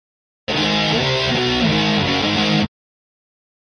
Guitar Heavy Metal Finale Chords -Long